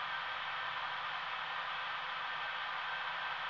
BackSound0083.wav